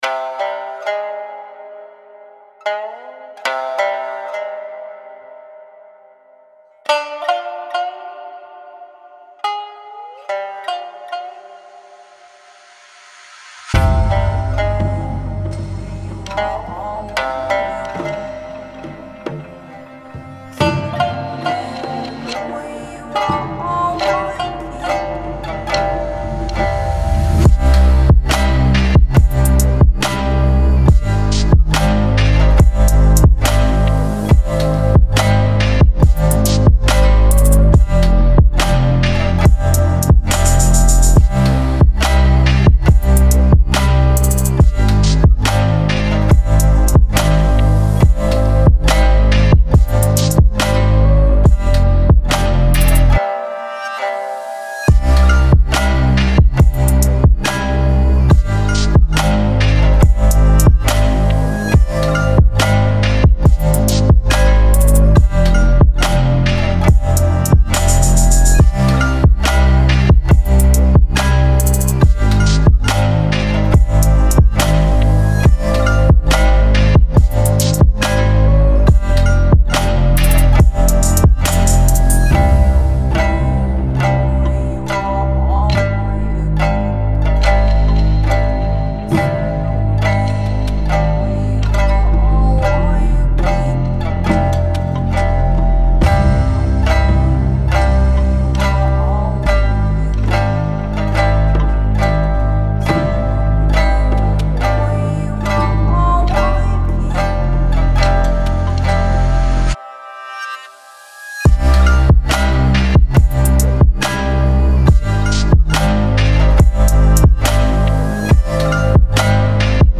Русский Рэп 2024 Поделись Треком